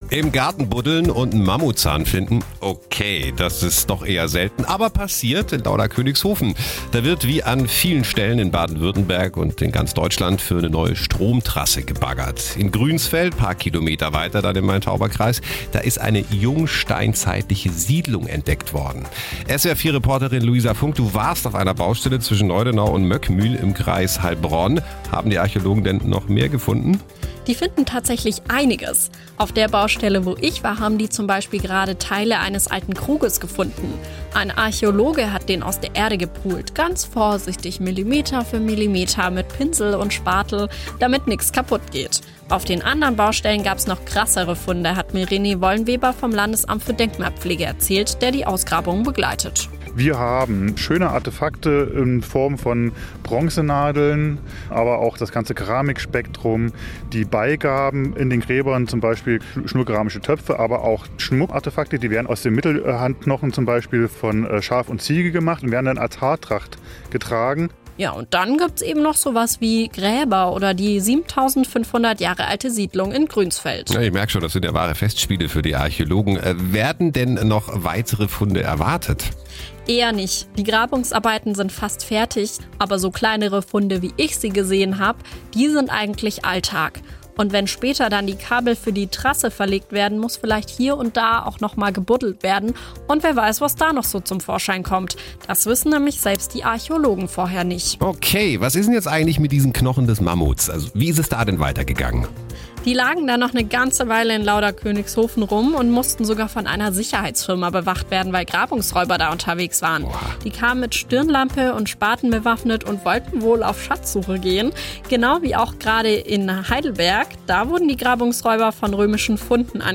SWR-Reporterin